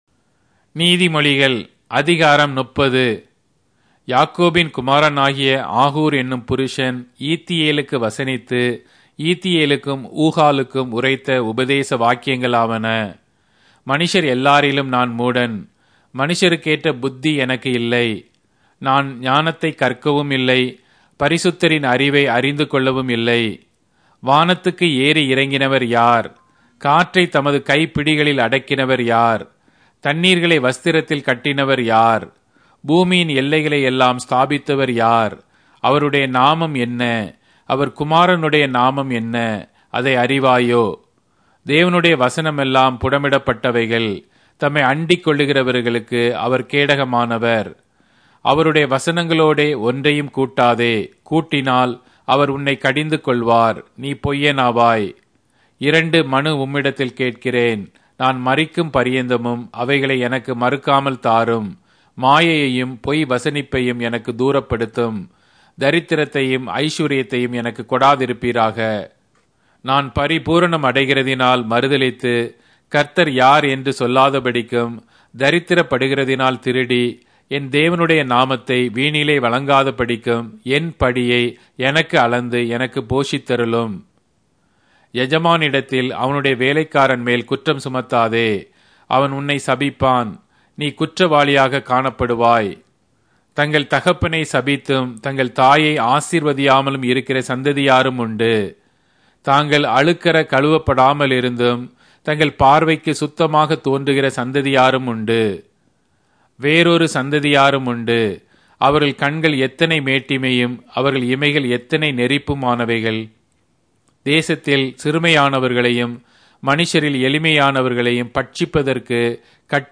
Tamil Audio Bible - Proverbs 28 in Gntbrp bible version